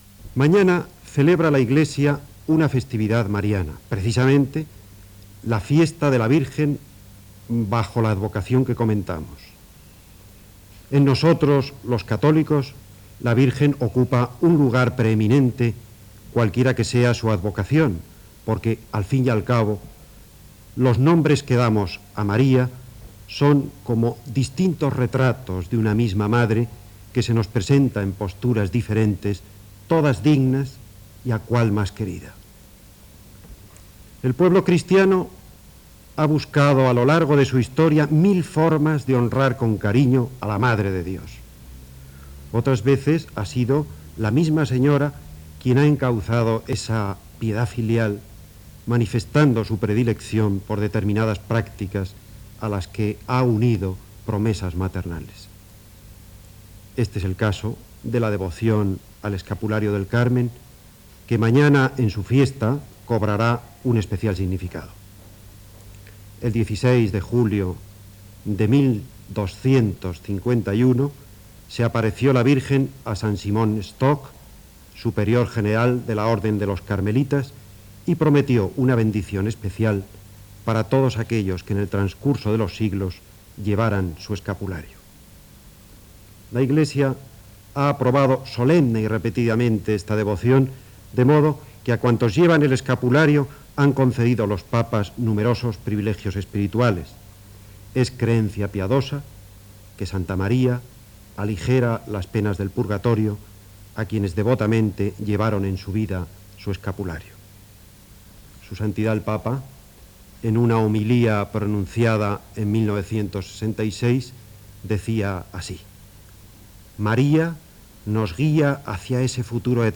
Comentari sobre la verge del Carme